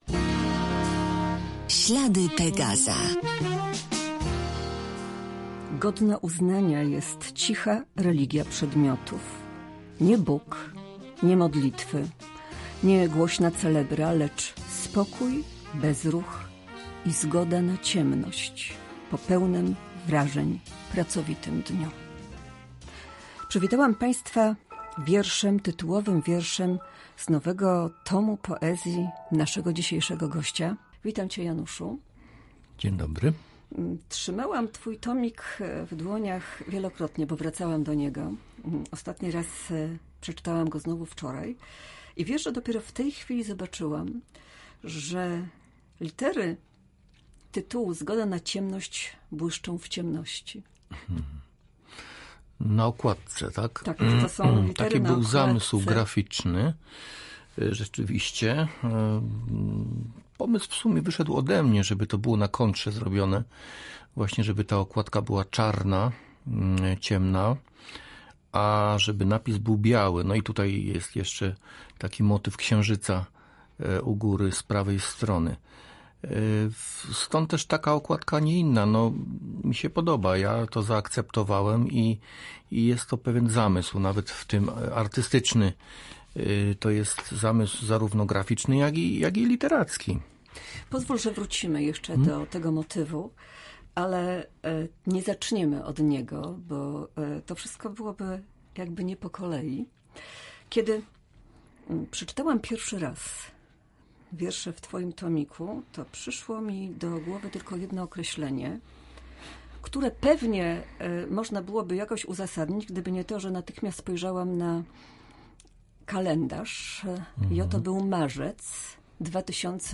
Wśród wątków rozmowy: temat wspomnień, przyszłości i odnajdywania porządku świata. Usłyszymy też fragmenty najnowszych wierszy.